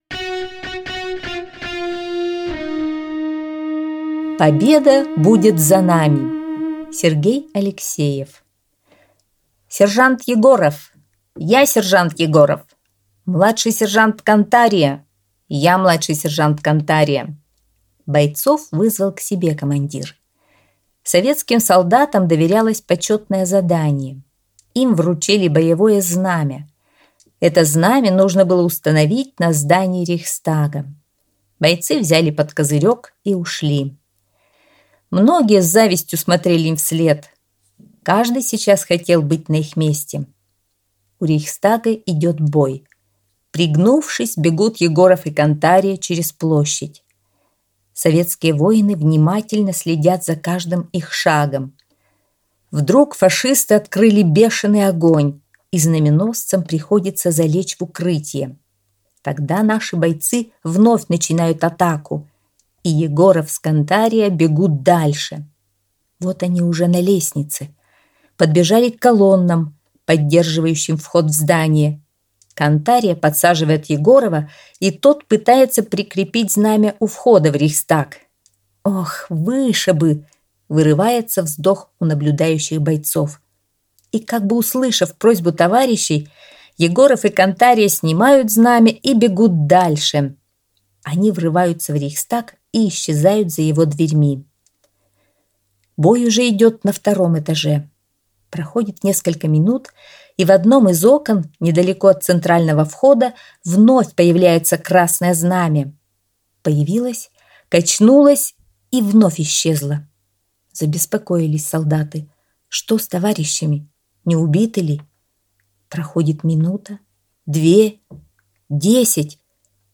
Аудиорассказ «Победа будет за нами»